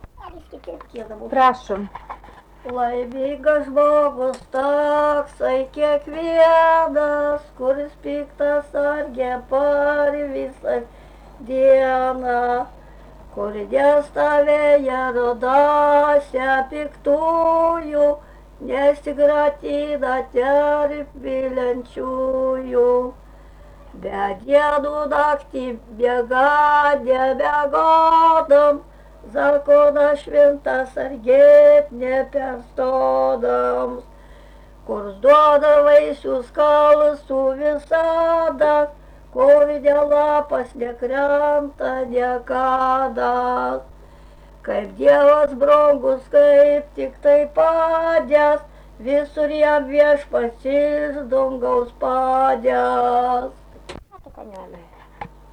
giesmė
Rageliai
vokalinis